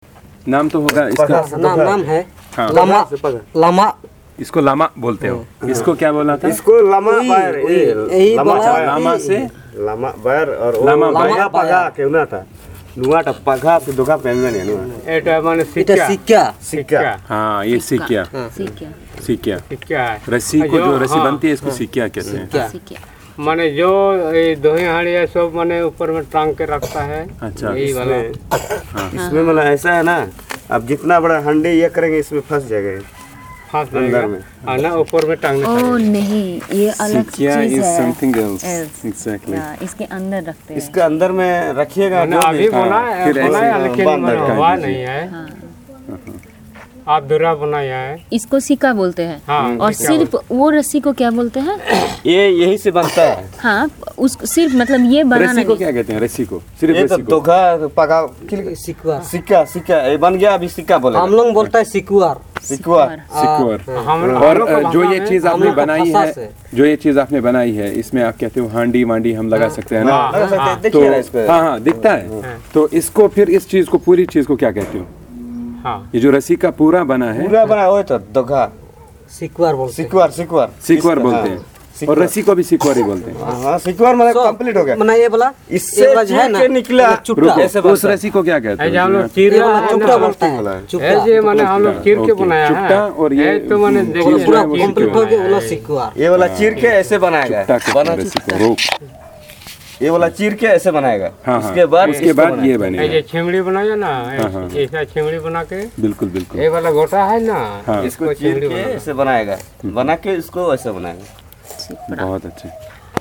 Elicitation of words related to household items